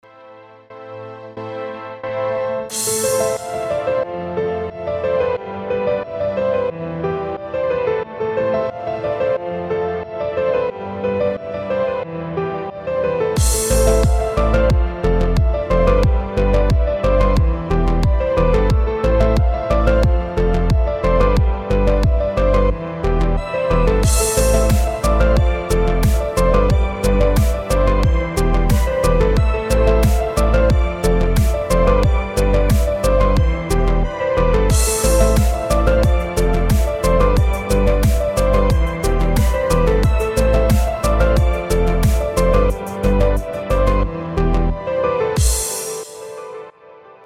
У твое писанины прослеживается бутырский ритм ...